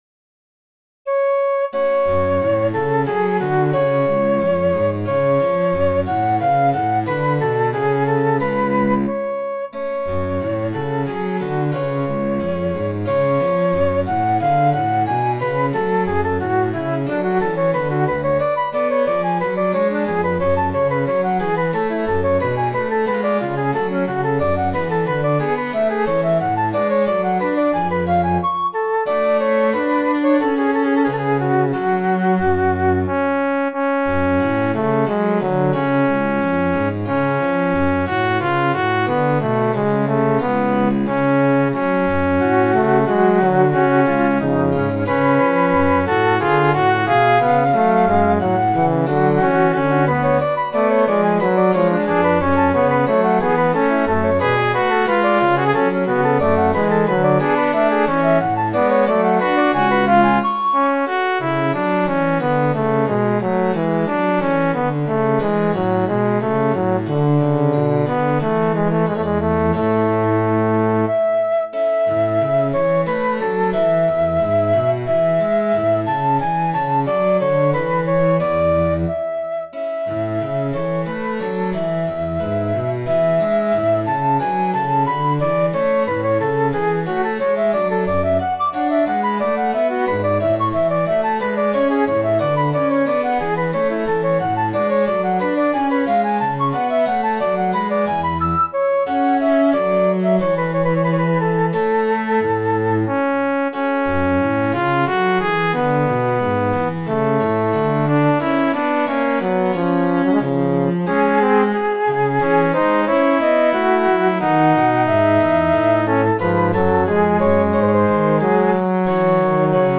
This is an aria in the late Baroque style for flute, tenor, and continuo. The text is taken from Goethe's Unbegrenzt (unbounded).